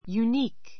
unique 中 juːníːk ユー ニ ー ク 形容詞 たった1つしかない , 唯一 ゆいいつ の, 類のない This is a unique fossil.